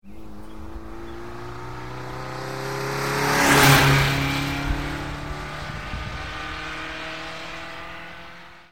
Lyssna på en Spider på tävlingsbanan.